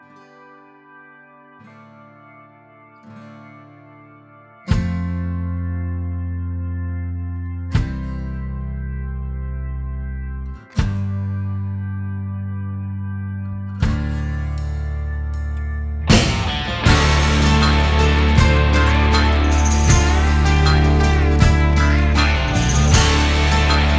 Minus Electric And Solo Rock 5:37 Buy £1.50